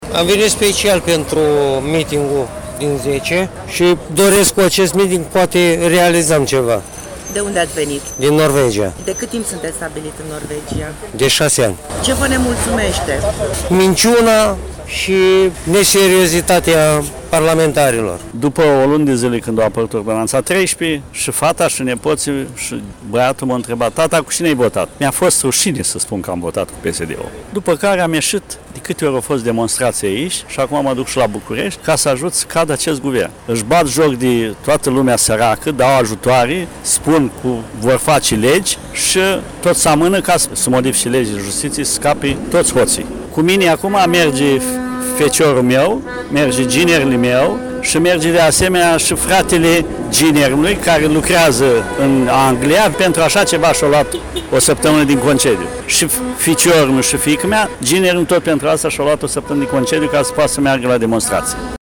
a stat de vorbă cu câțiva protestatari.